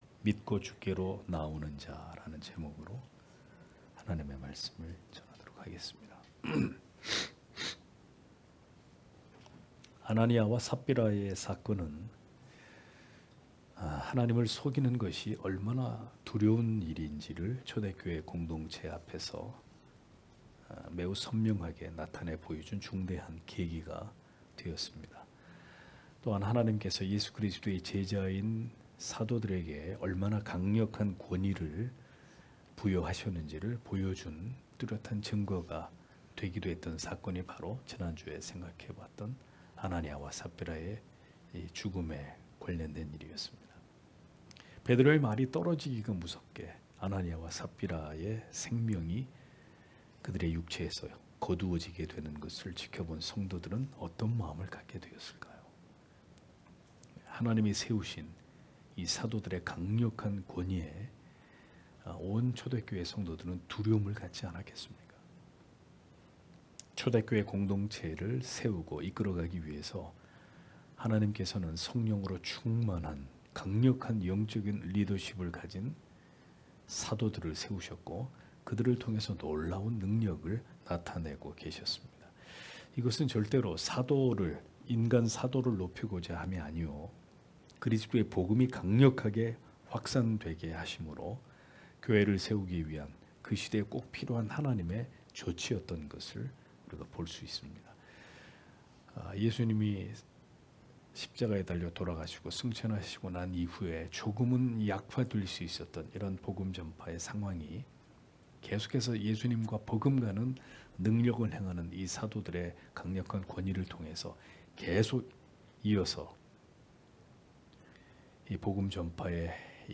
금요기도회